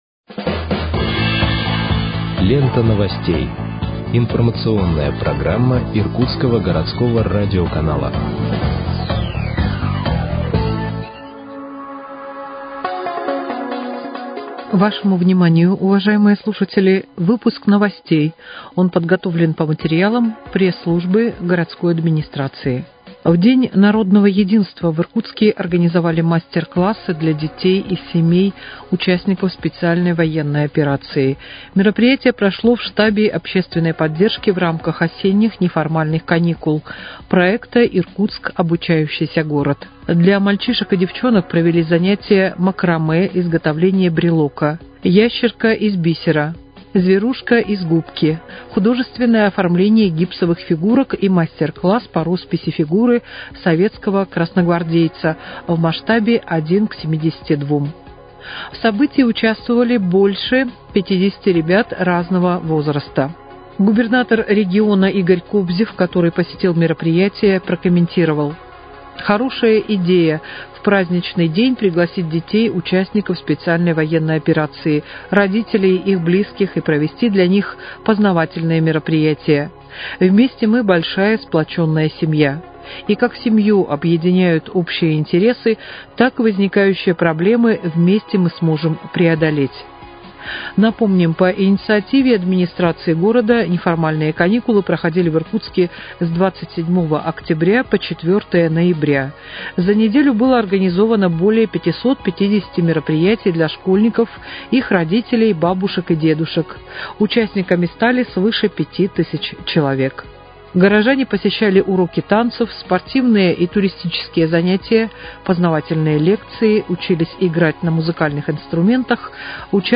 Выпуск новостей в подкастах газеты «Иркутск» от 7.11.2025 № 1